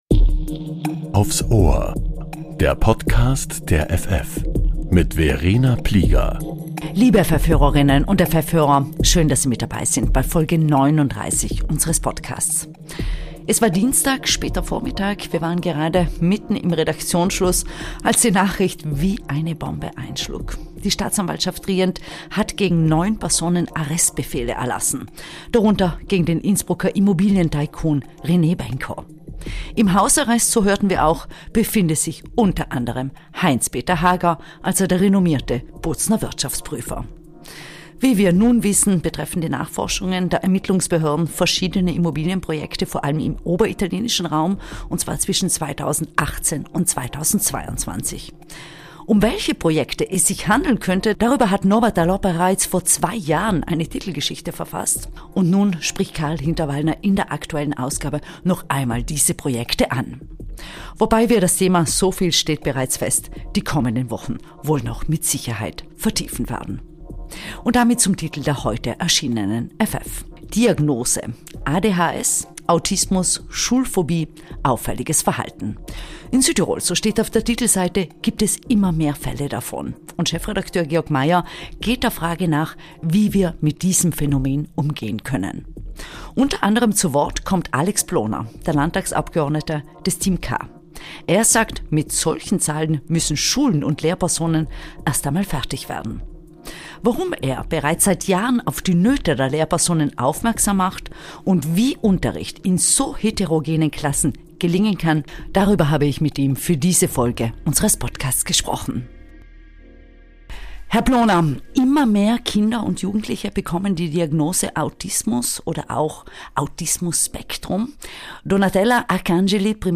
In Südtirol gibt es immer mehr Fälle von ADHS, Autismus, Schulphobie oder auffälligem Verhalten. Wie Südtirols Schule damit umgeht, darüber spricht der Landtagsabgeordnete Alex Ploner.